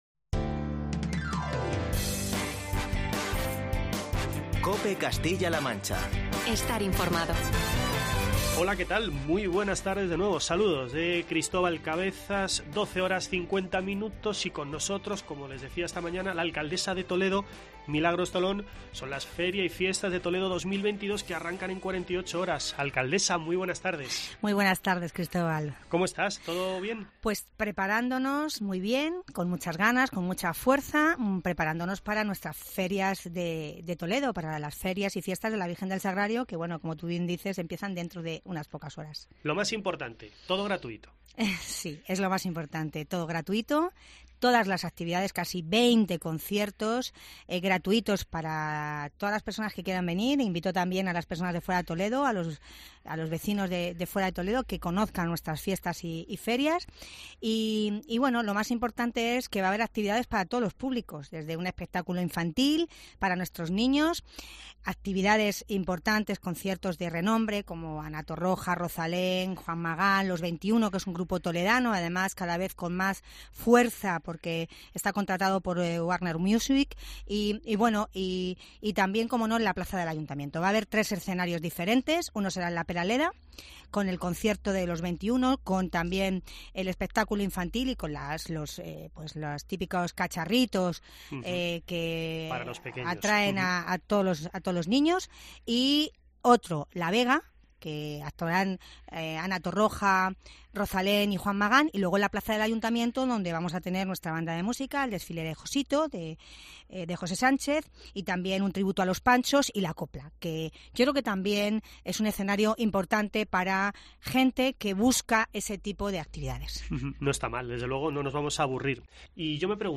Entrevista
No te pierdas la entrevista con la alcaldesa de Toledo